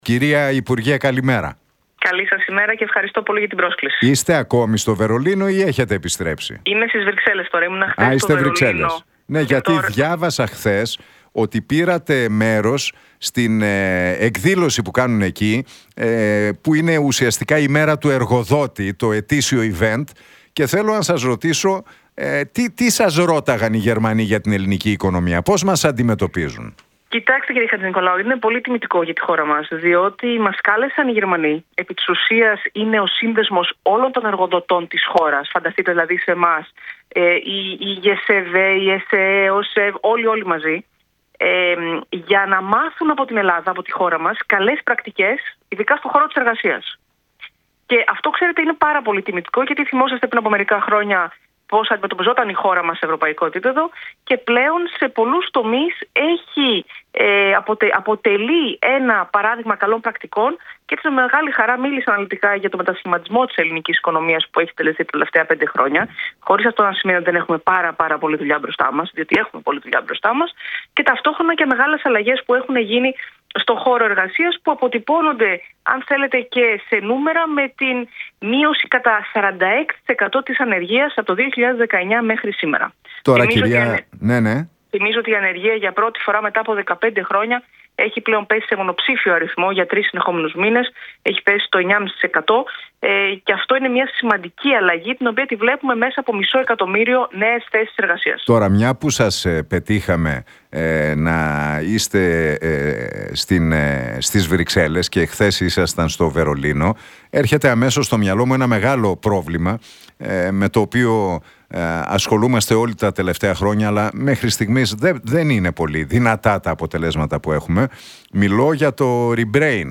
Νίκη Κεραμέως στον Realfm 97,8: Ο κατώτατος μισθός δεν θα μπορεί να μειωθεί – Πώς θα λειτουργεί ο νέος μηχανισμός υπολογισμού